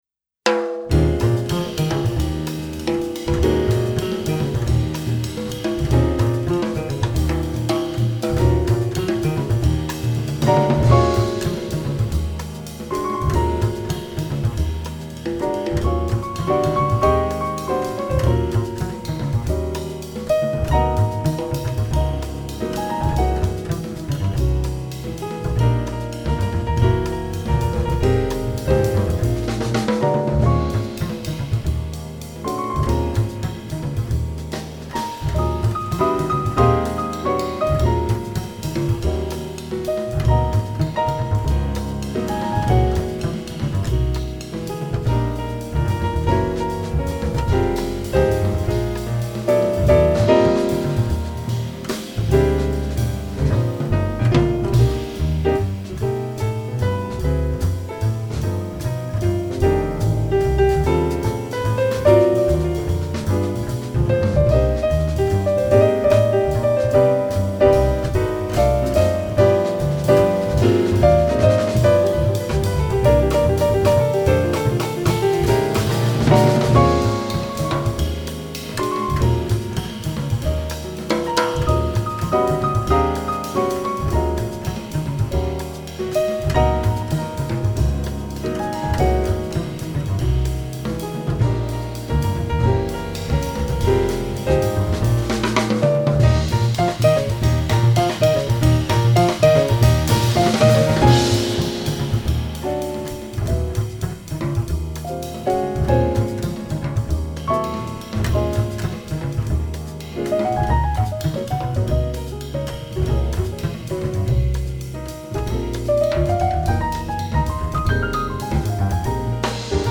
Jazz interpretations